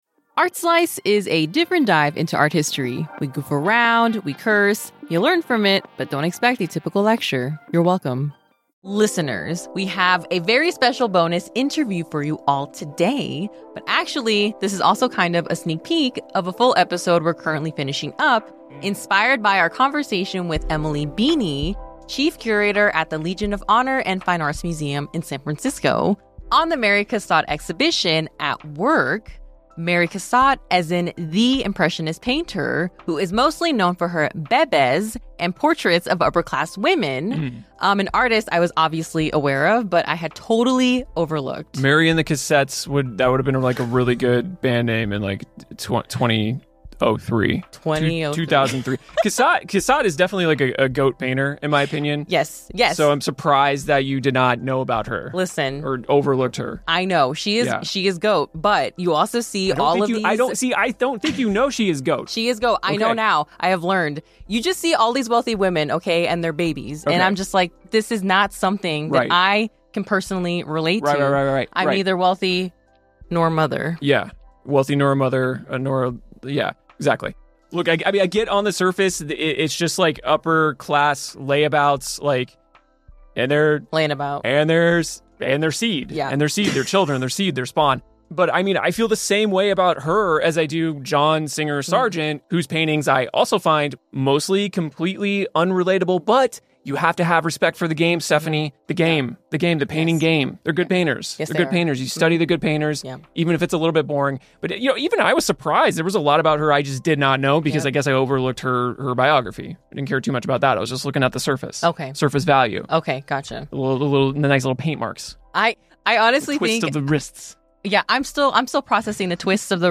We present a very special bonus interview